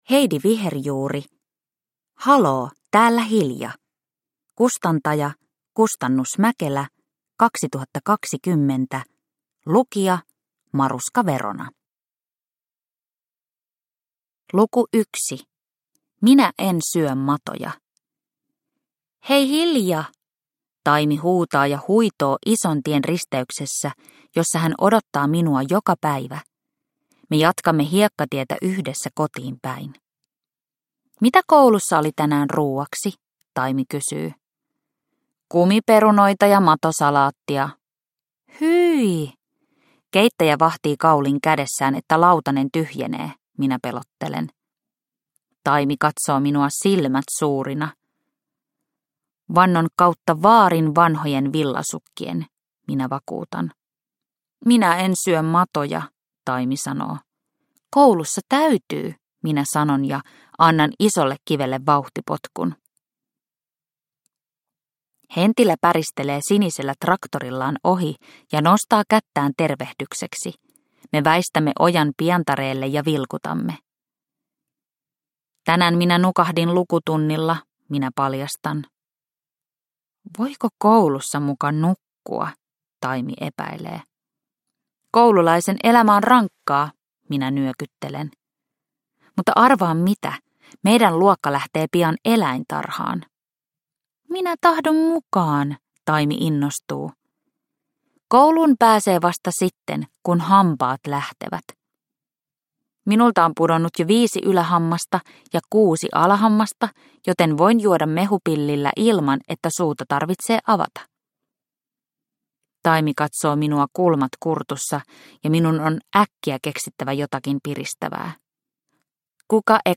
Haloo, täällä Hilja – Ljudbok – Laddas ner